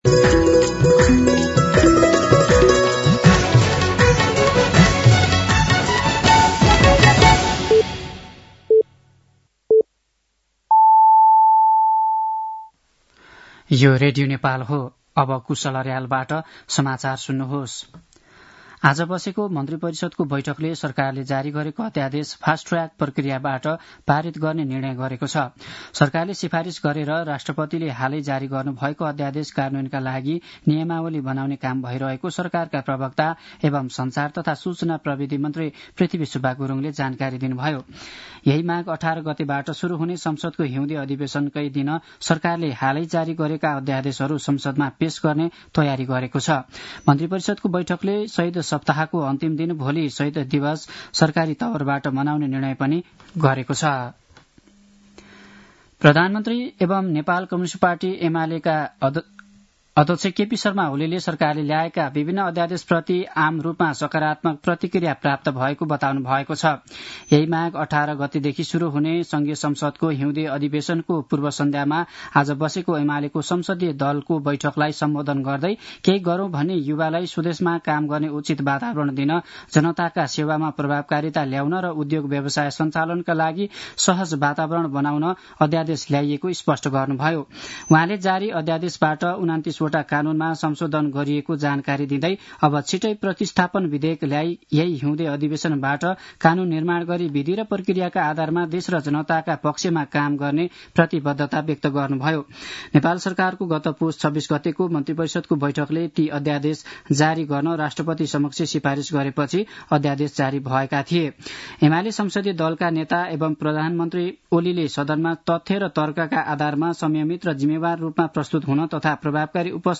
साँझ ५ बजेको नेपाली समाचार : १६ माघ , २०८१